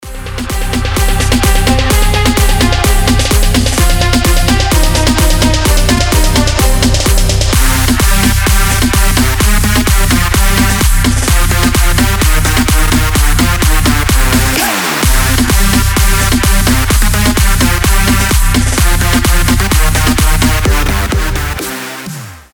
• Качество: 320, Stereo
громкие
жесткие
мощные
EDM
без слов
качающие
взрывные
энергичные
Big Room
electro house
Динамичный клубняк станет отличным громким звонком